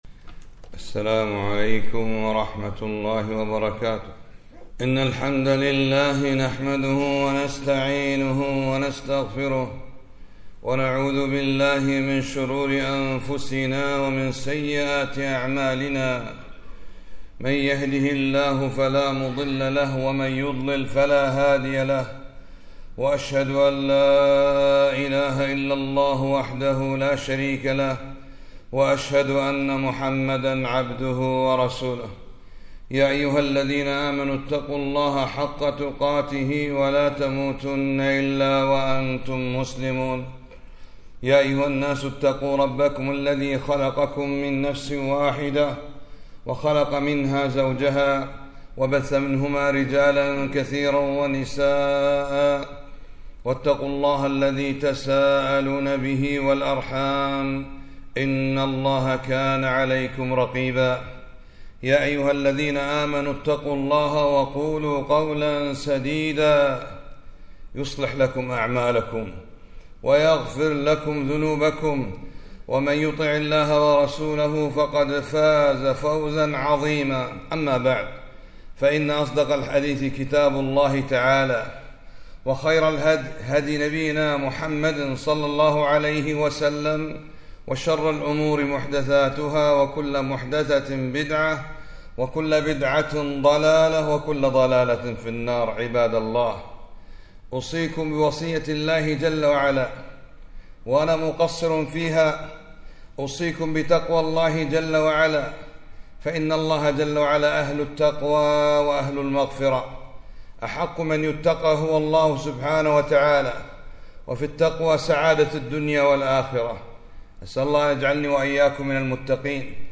خطبة - إن الله هو الغني